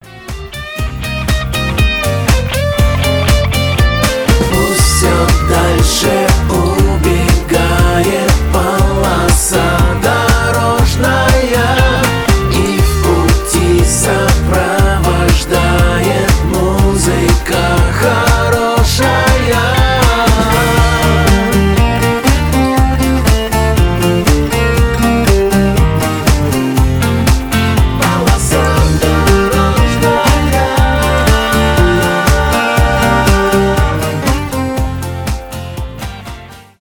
позитивные
авторская песня
шансон